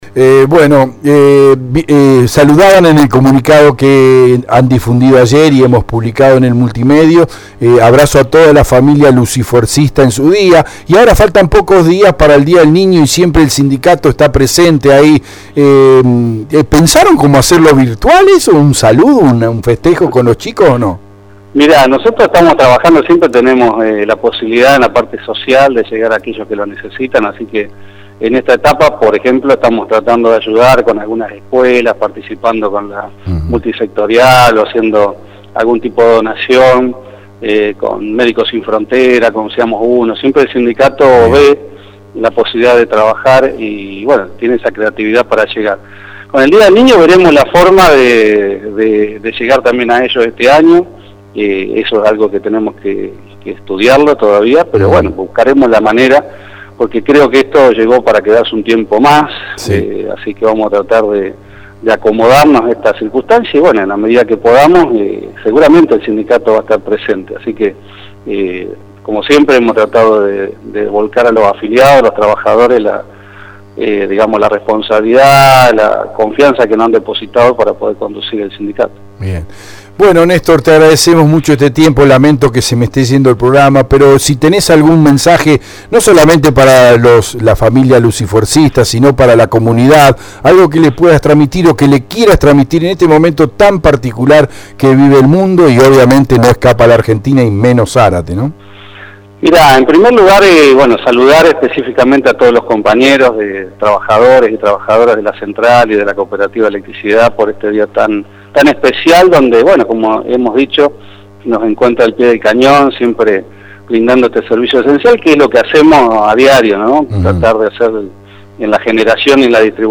Antes de terminar la entrevista, expresó “quiero saludar a todos los compañeros de trabajadores y trabajadoras de la Central y de la Cooperativa de Electricidad en este día tan especial y como habíamos dicho siempre vamos a estar brindando el servicio nuestro para la comunidad de la zona”, y respecto a su pensar lo que está por venir, post pandemia,  manifestó “Yo creo que a veces el ser humano tiene que tocar ciertos límites para poder darse cuenta que las personas somos frágiles y que, tenemos un tiempo limitado en esta tierra y que debemos reconocer que no nos vamos a llevar nada de lo que tenemos”, terminó.